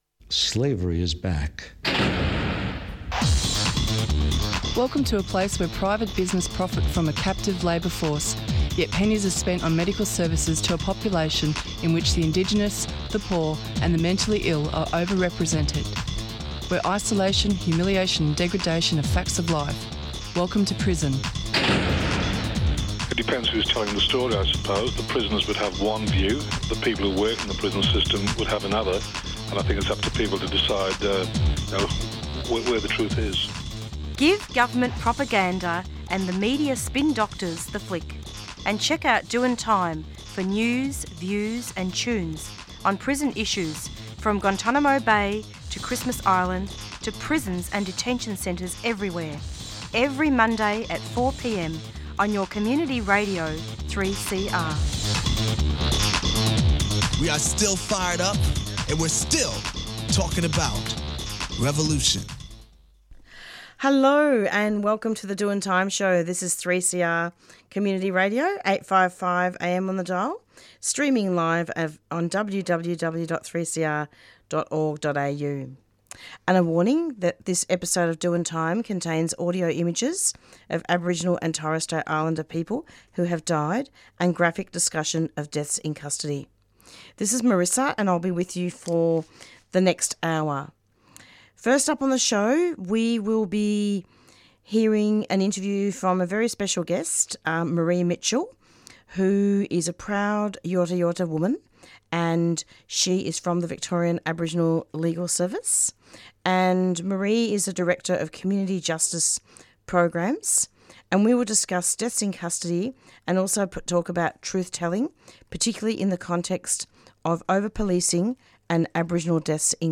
*This episode of Doin' Time contains audio images of Aboriginal and Torres Strait Islander people who have died, and discussion of Deaths in Custody.